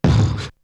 Beatbox 3.wav